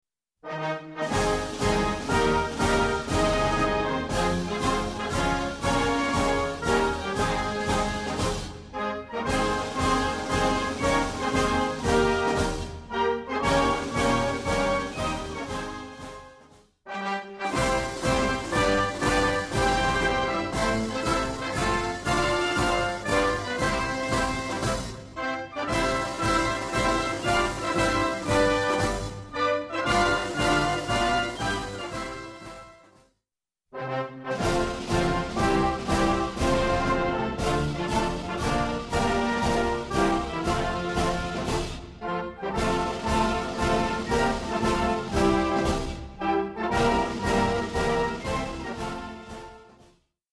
La velocità è identica Esatto! Ogni volta l'inno si colloca su una diversa "area tonale".